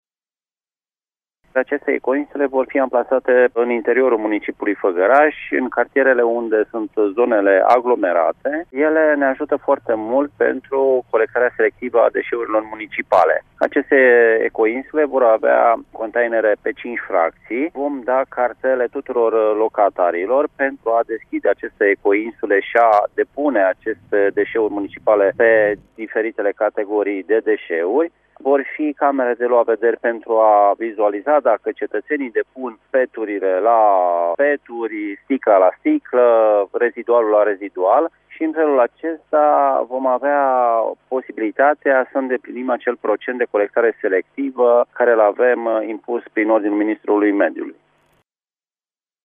Primarul municipiului Făgăraș, Gheorghe Sucaciu.